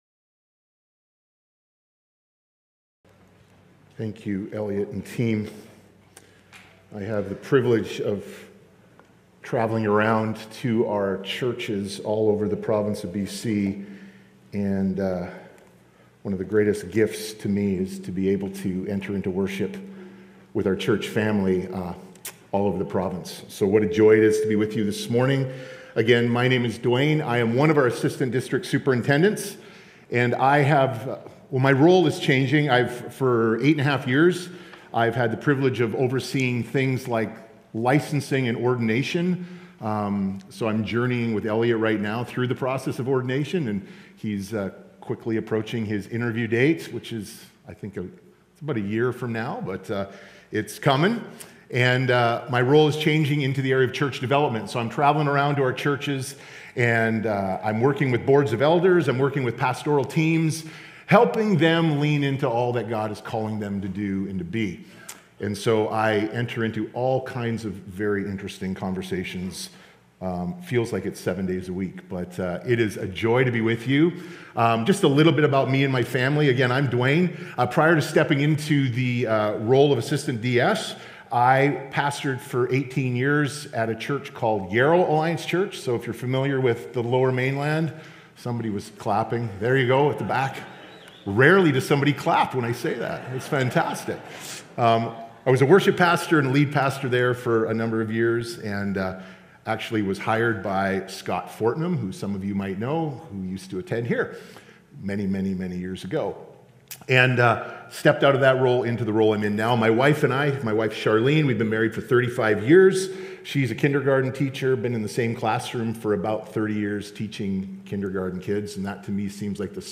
Hear the latest sermons from Mission Creek Alliance Church in Kelowna, BC, Canada.